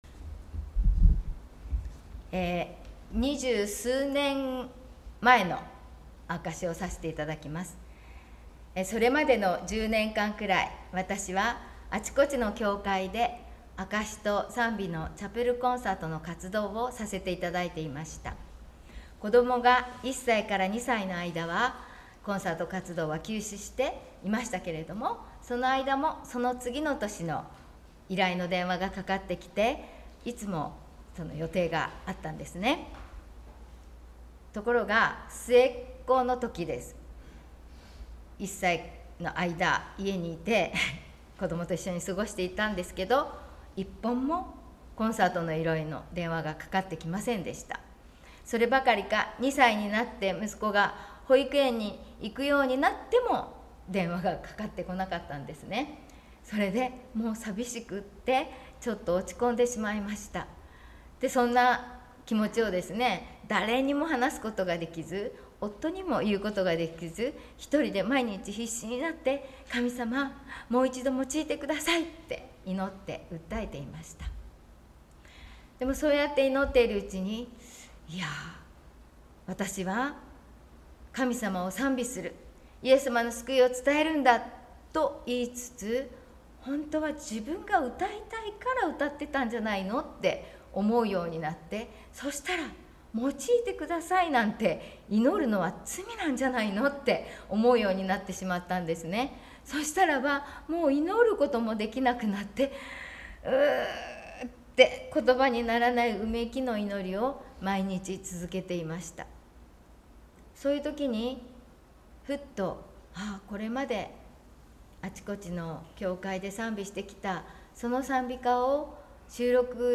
証し